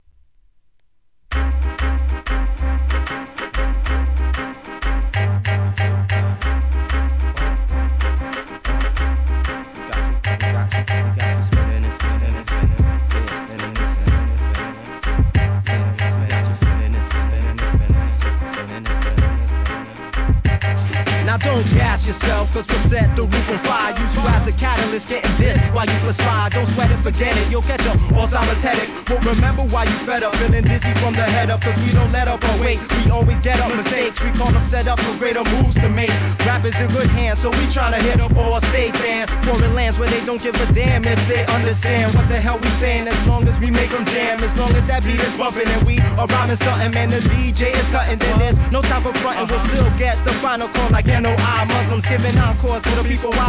HIP HOP/R&B
シンプルながら中毒性あり!!